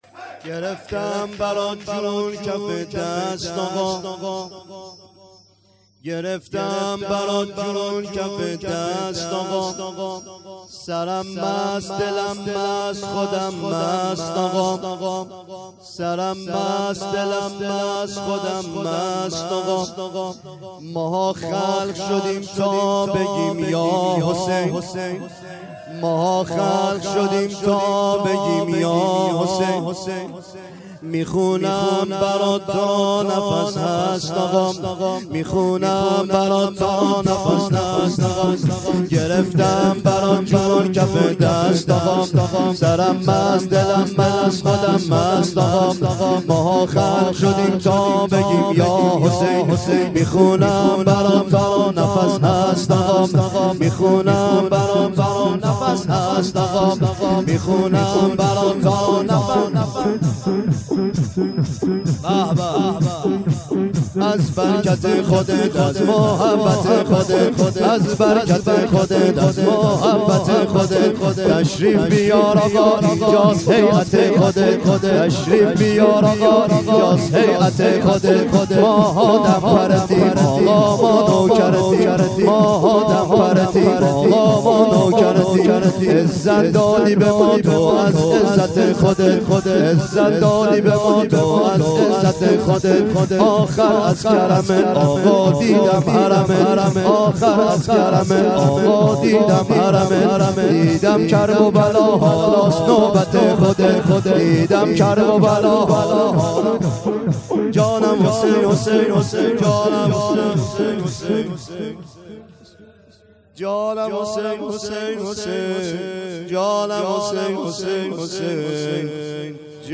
شور : گرفتم برات جون کفه دست آقا